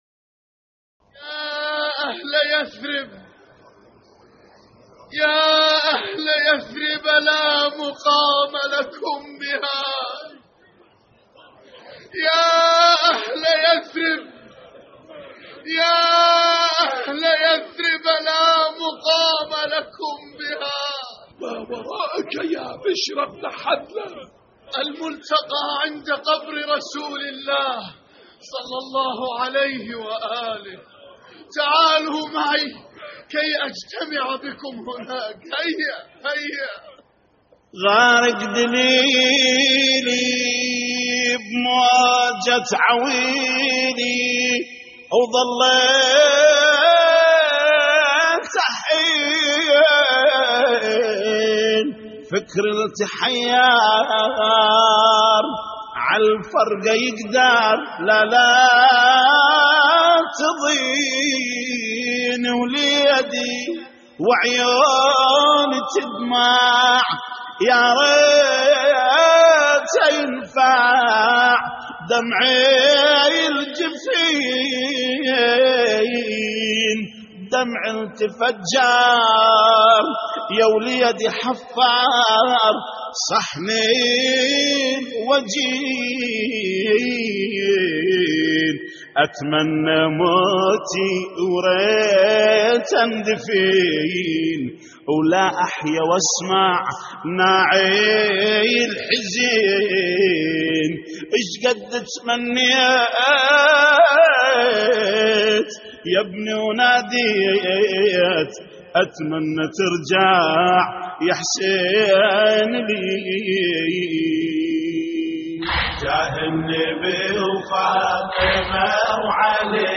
تحميل : يا اهل يثرب لا مقام لكم بها + بجاه النبي وفاطمة وعلي اتمنى ترجع يا حسين / الرادود جليل الكربلائي / اللطميات الحسينية / موقع يا حسين